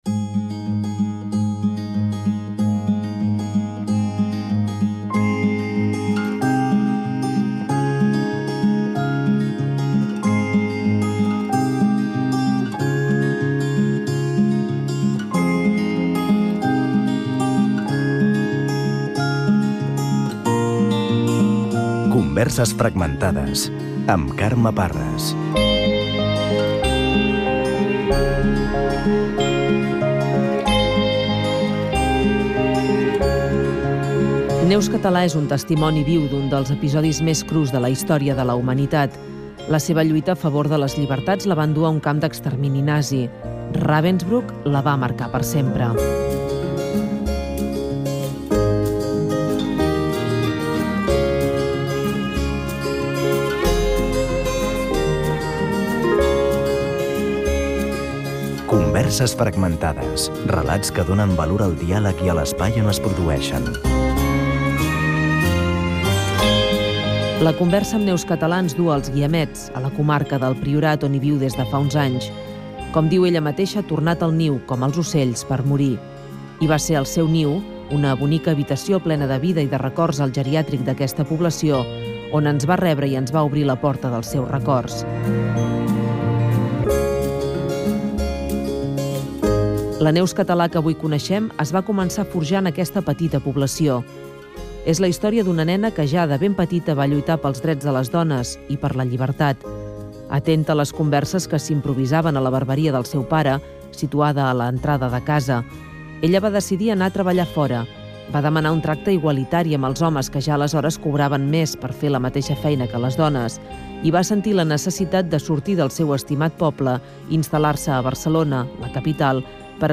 Careta del programa, biografia i conversa amb Neus Català des de Els Guiamets. Català va patir la repressió nazi al camp de concentració de Ravensbrück.